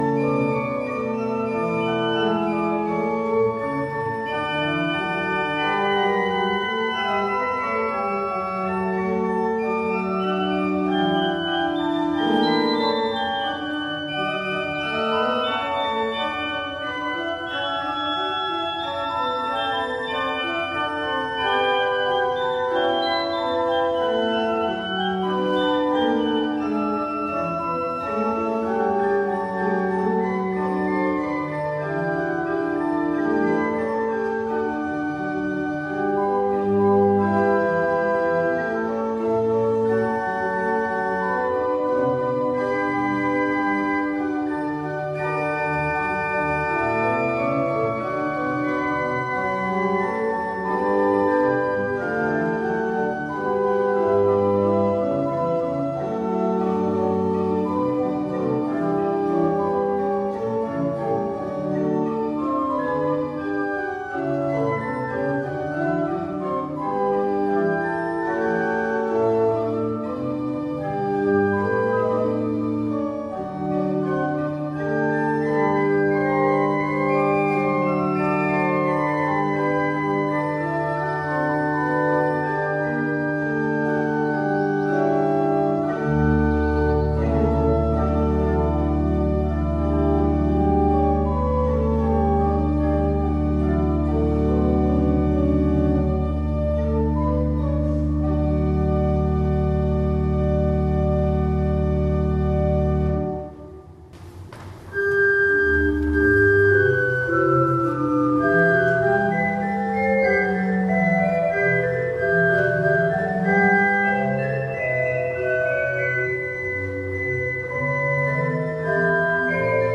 Predigten - Zionsgemeinde Hartenstein
John 8:31-36 Dienstart: Gottesdienst Altes Testament 13.Sonntag nach Trinitatis 6.